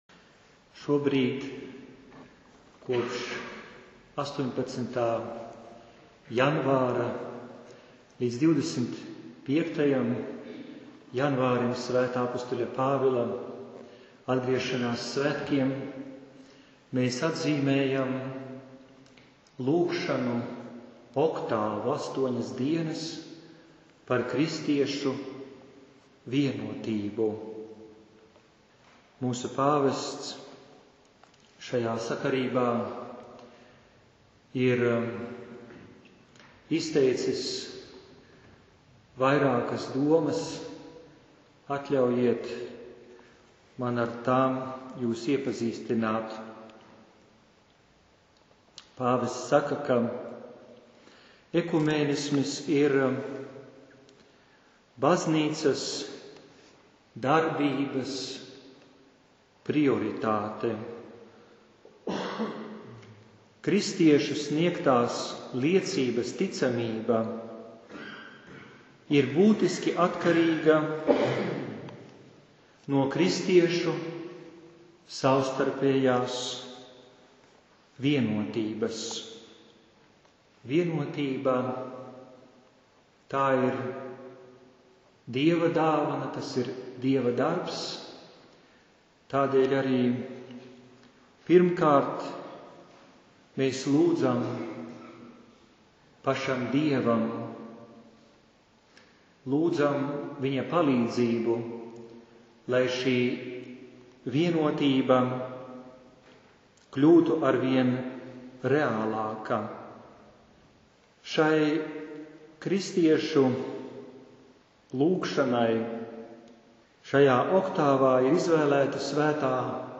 Noklausies: Liepājas bīskapa sprediķis Kuldīgā 01.23.2012.
Liepajas_biskapa_spredikis_Kuldiga.mp3